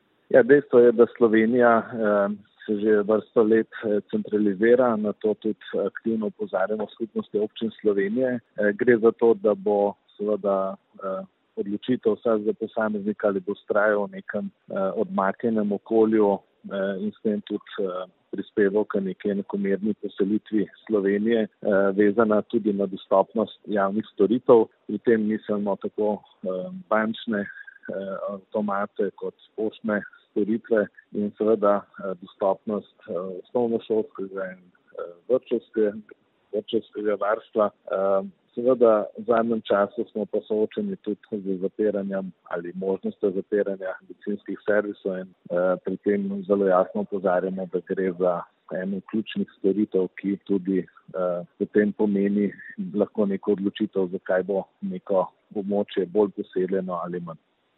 Zdaj se temu seznamu pridružujejo še bencinski servisi, kar po mnenju Skupnosti občin Slovenije predstavlja rdeči alarm. Predsednik Skupnosti občin Slovenije Gregor Macedoni:
izjava Maceodni - SOS  za splet.mp3